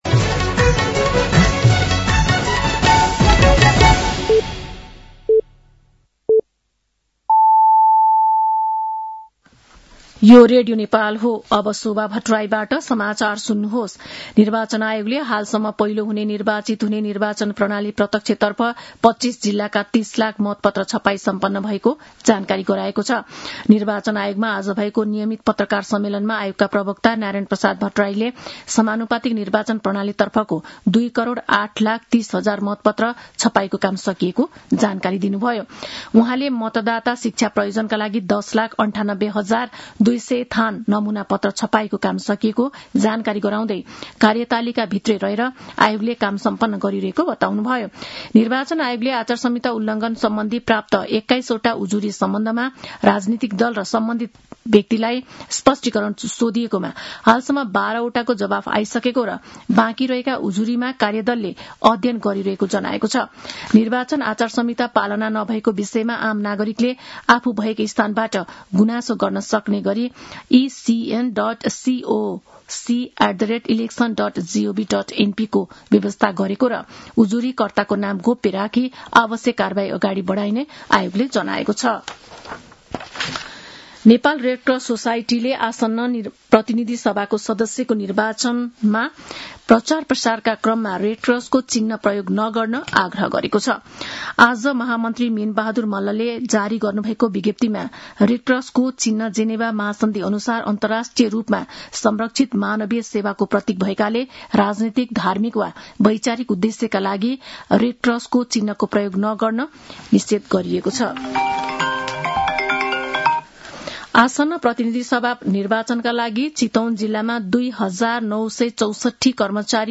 साँझ ५ बजेको नेपाली समाचार : १८ माघ , २०८२
5.-pm-nepali-news-.mp3